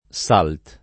[ S alt ]